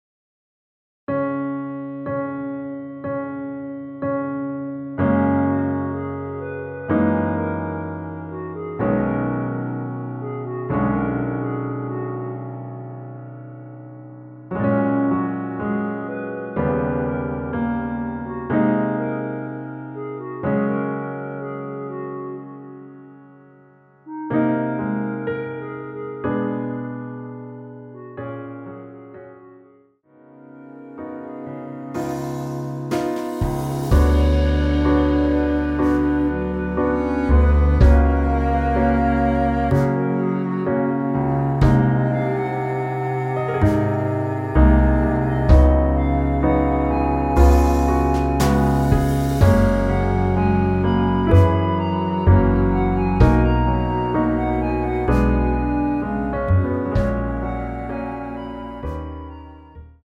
원키에서(-1)내린 멜로디 포함된 MR입니다.
F#
앞부분30초, 뒷부분30초씩 편집해서 올려 드리고 있습니다.
중간에 음이 끈어지고 다시 나오는 이유는